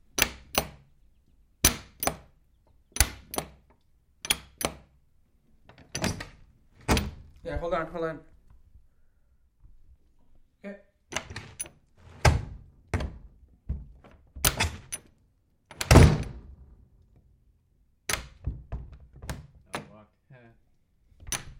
门和旋钮 " 门锁了03
描述：门锁抖动
标签： 关闭 吱吱 lonng 屏幕 解锁 木材